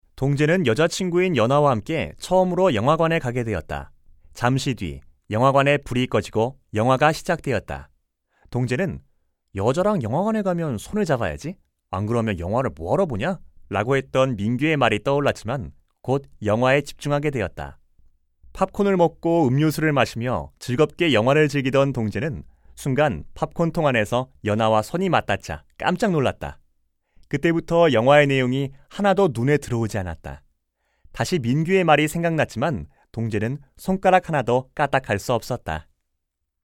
114쪽-내레이션.mp3